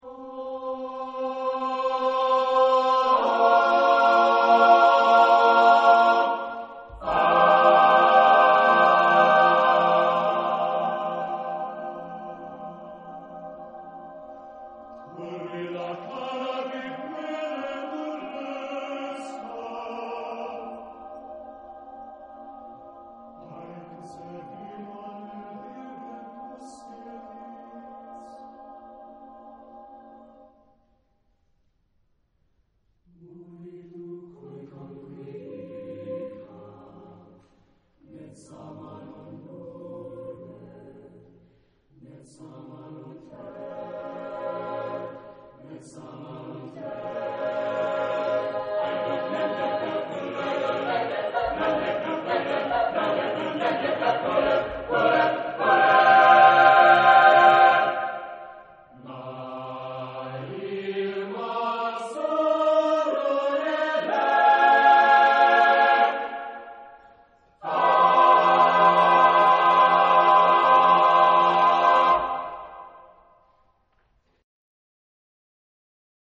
Genre-Style-Form: Contemporary ; Partsong ; Secular
Type of Choir: SSAATTBB  (8 mixed voices )
Tonality: F minor